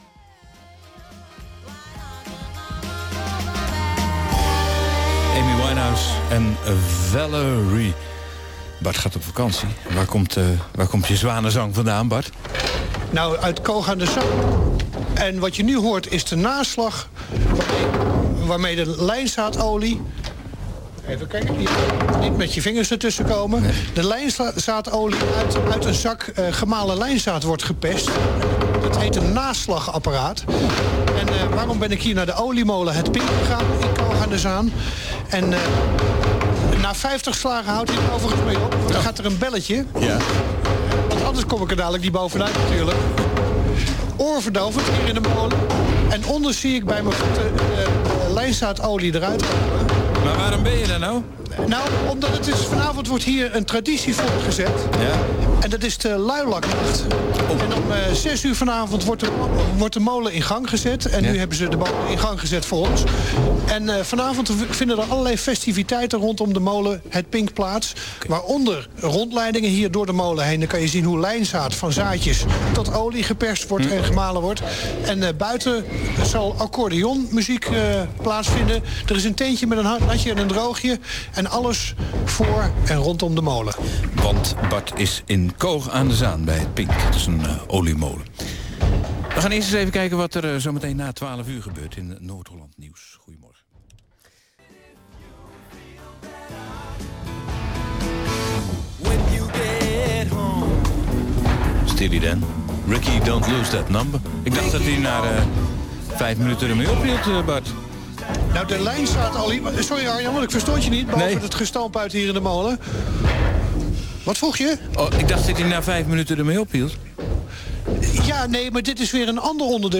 Om iets over half twaalf kwamen wij, al slaande, live op de radio met een aankondiging voor ons luilak evenement.